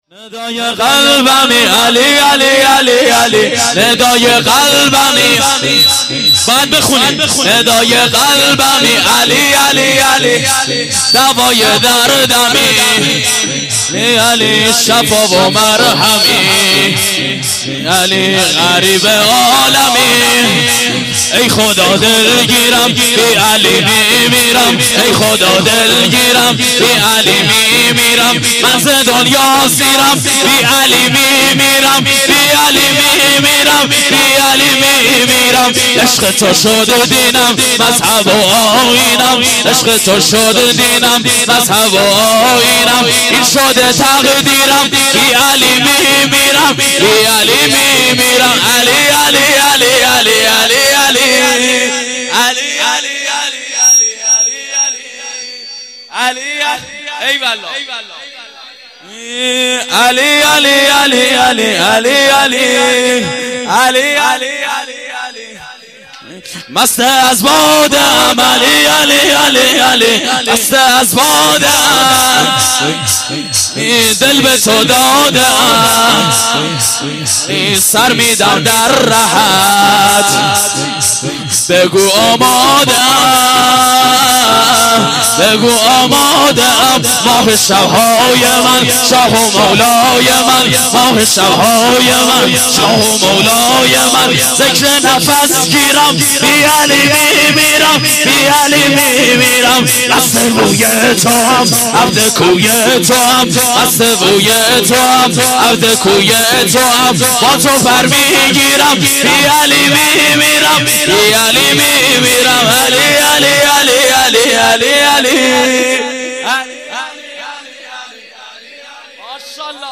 شور - ندای قلبمی علی علی